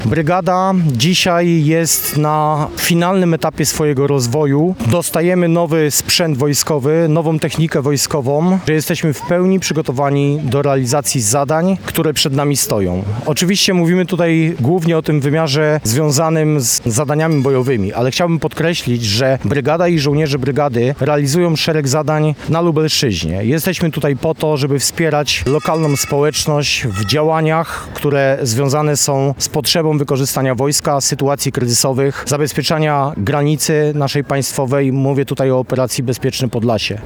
Uroczysty capstrzyk z okazji święta 19 Lubelskiej Brygady Zmechanizowanej im. gen. dyw. Franciszka Kleeberga odbył się wieczorem na placu Litewskim w Lublinie.